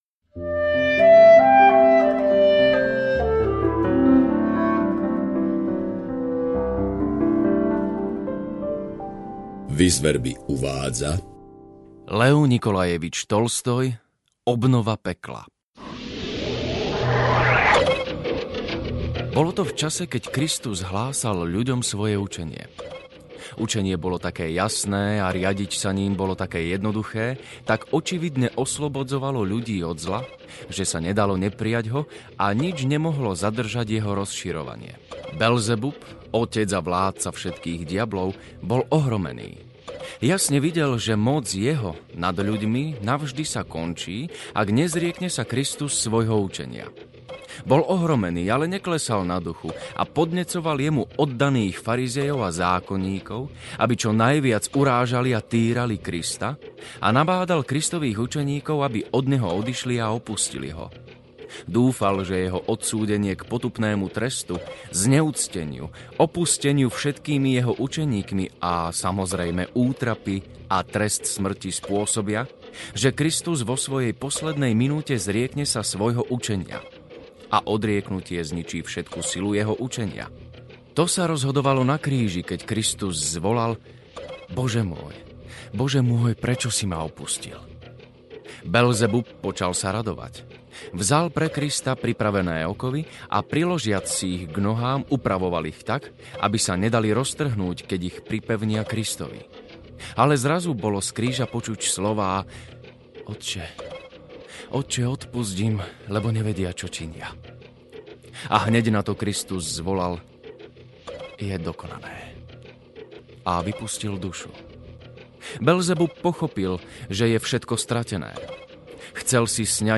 Obnova pekla audiokniha
Ukázka z knihy